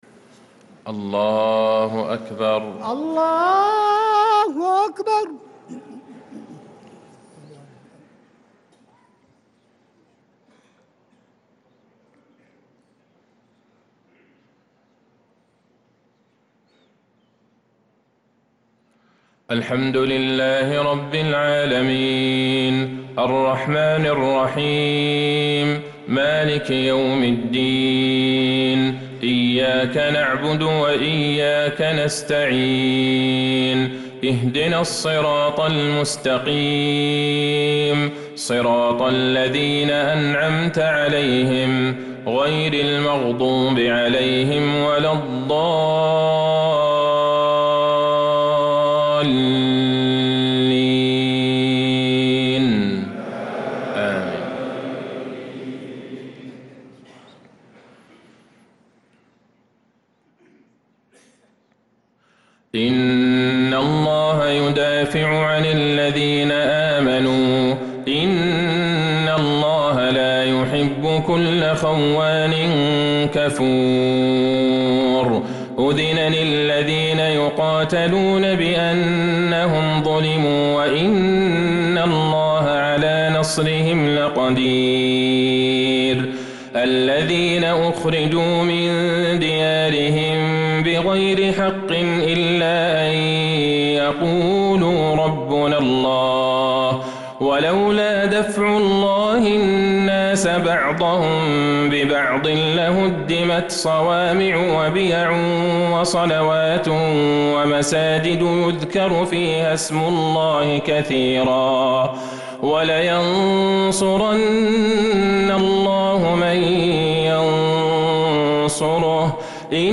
صلاة العشاء للقارئ عبدالله البعيجان 20 ربيع الآخر 1446 هـ
تِلَاوَات الْحَرَمَيْن .